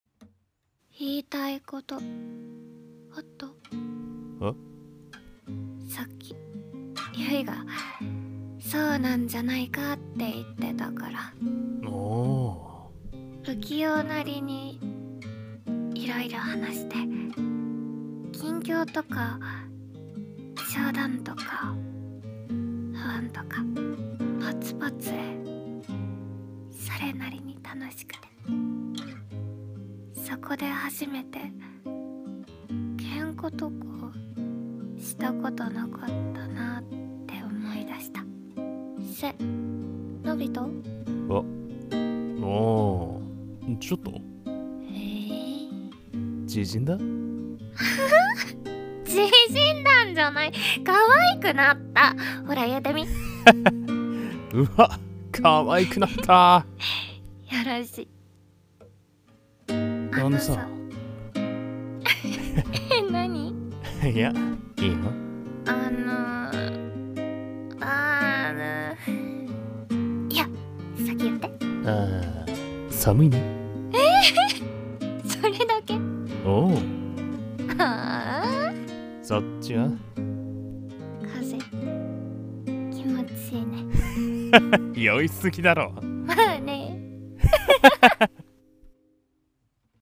声劇台本】あのさ